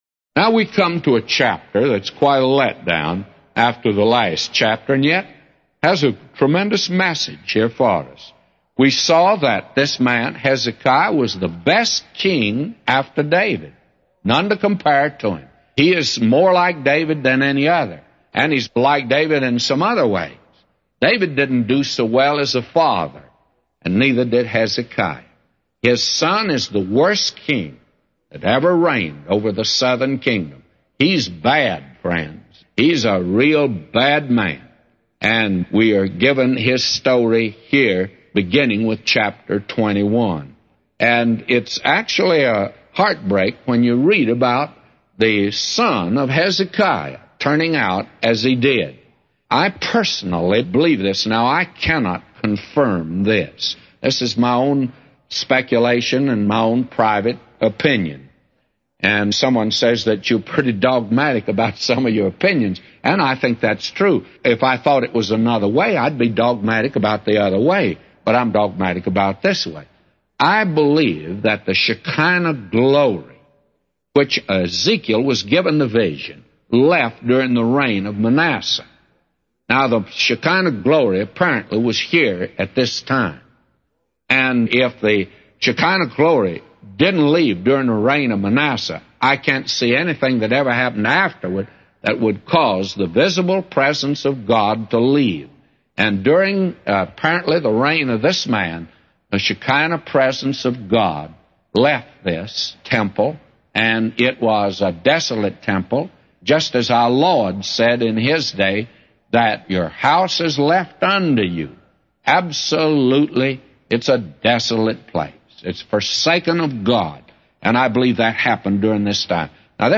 A Commentary By J Vernon MCgee For 2 Kings 21:1-999